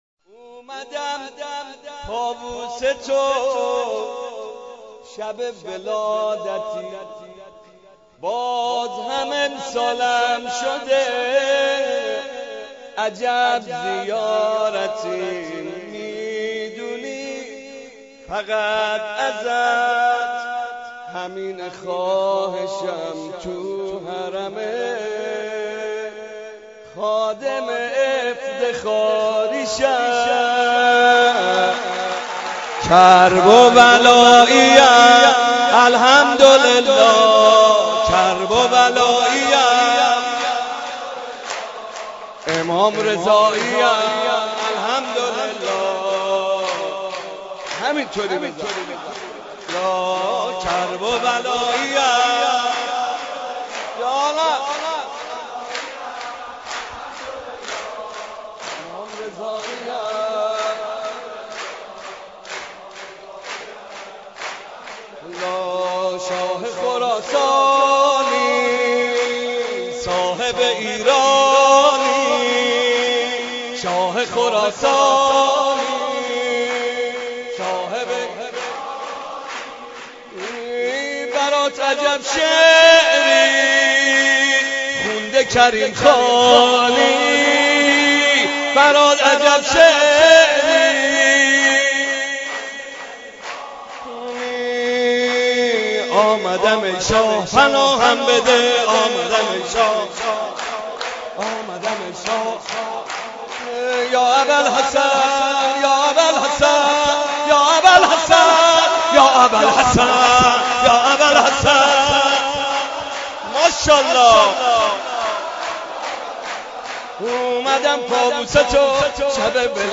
مولودی‌خوانی محمدرضا طاهری برای ولادت حضرت رضا (ع) را می‌شنوید.